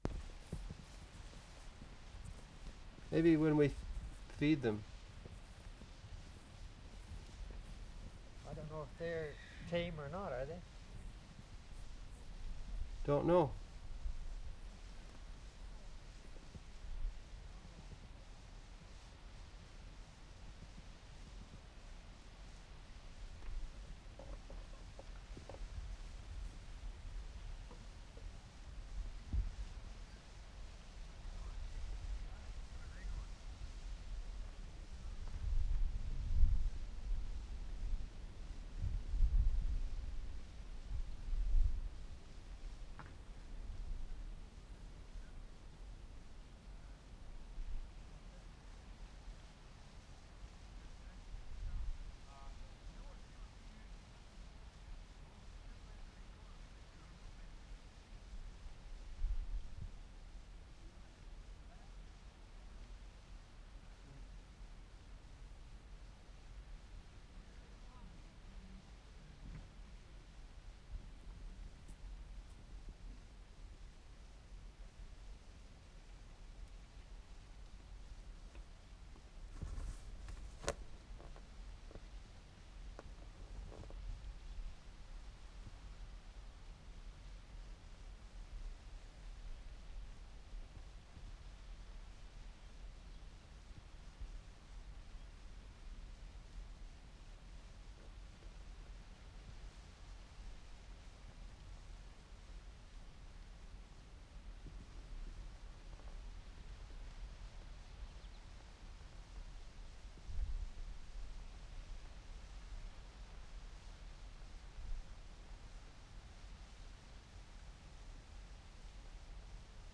WORLD SOUNDSCAPE PROJECT TAPE LIBRARY
ALLIANCE, ALBERTA Sept. 23, 1973
HUSE FARM, preparations to horse feeding 4'33"
3. Quiet wind ambience with people talking in background. Insect "zips" by microphone (2'56").